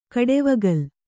♪ kaḍevagal